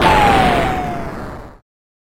描述：复古视频游戏8位爆炸
Tag: 复古 爆炸 8位 视频游戏